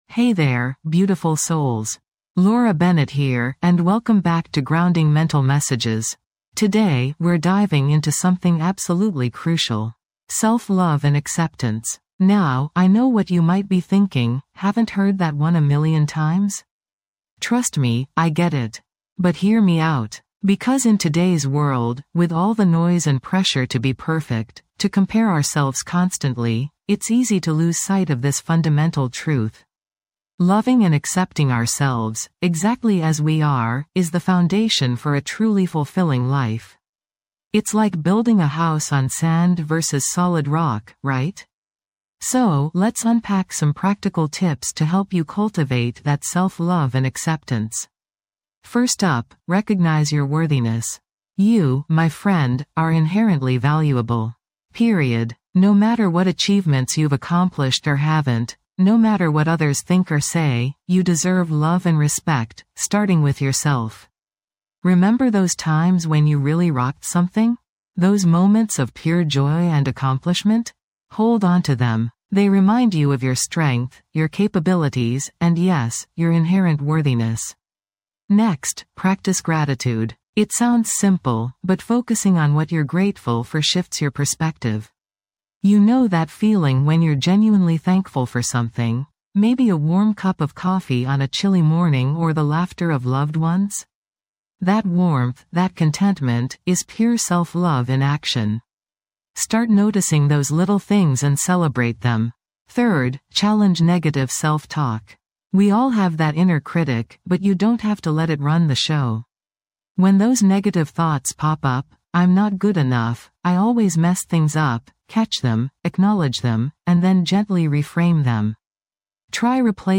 Through soothing guided meditations, mindfulness exercises, and calming soundscapes, this podcast offers practical tools to navigate the challenges of everyday life. Each episode is designed to anchor you in the present moment, reducing stress, promoting self-awareness, and fostering a deeper sense of well-being.